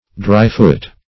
Search Result for " dryfoot" : The Collaborative International Dictionary of English v.0.48: Dryfoot \Dry"foot\, n. The scent of the game, as far as it can be traced.